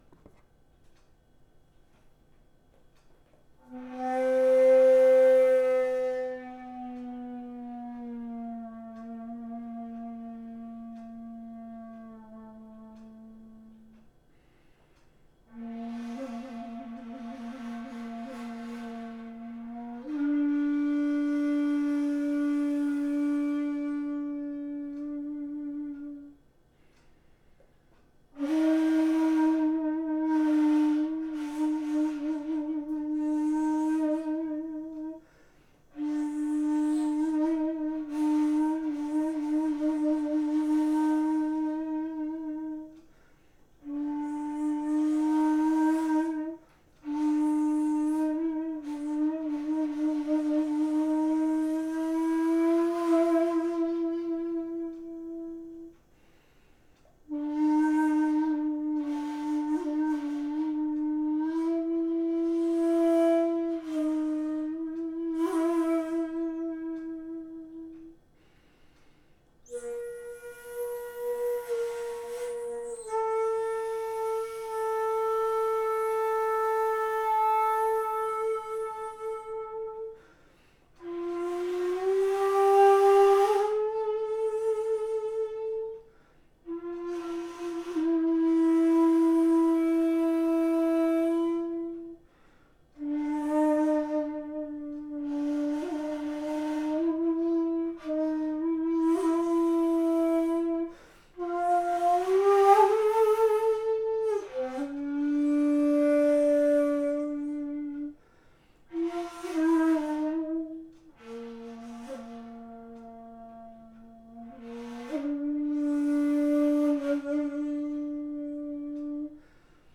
（音声ファイル：長管夕暮の曲）